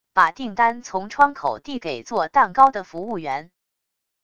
把订单从窗口递给做蛋糕的服务员wav音频